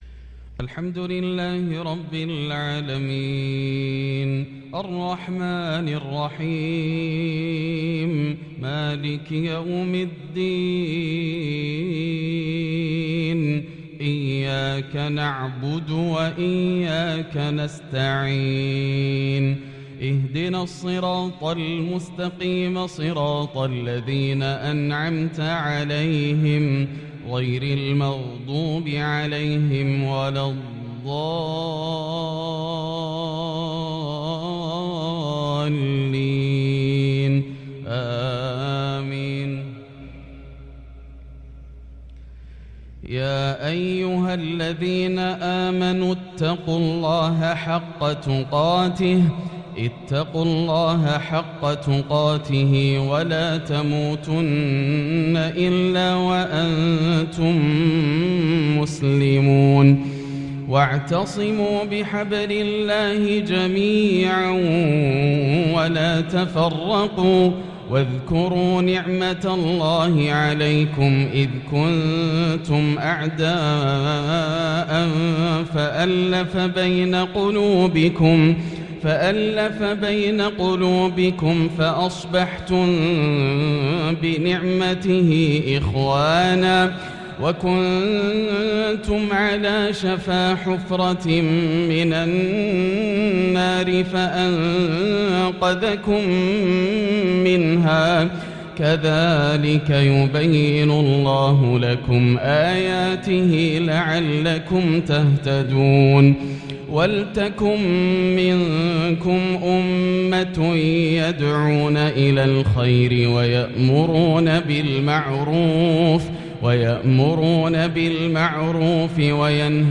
مغرب الاثنين 6-7-1443هـ من سورة آل عمران | Maghrib prayer from Surah Al-Imran 7-2-2022 > 1443 🕋 > الفروض - تلاوات الحرمين